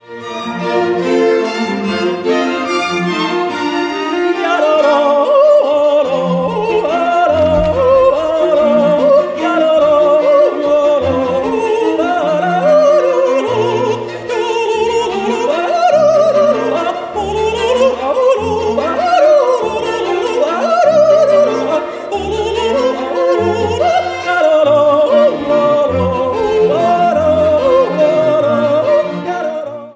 for yodel solo and string quartet
Description:Classical; chamber music
Temple, Château-d'Oex